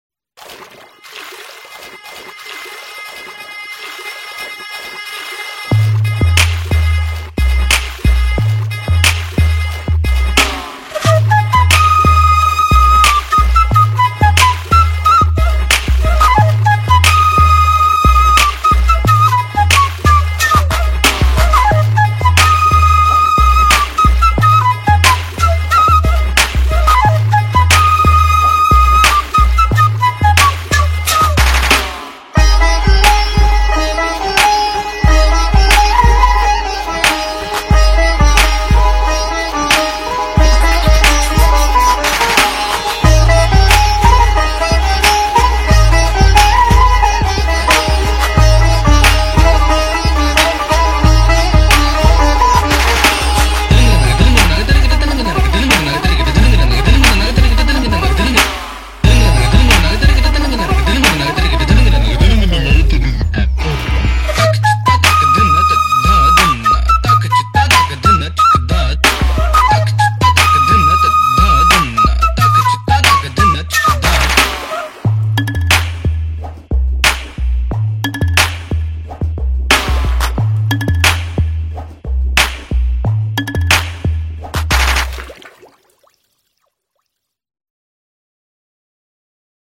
water_effect.mp3